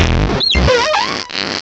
Cri de Moufflair dans Pokémon Diamant et Perle.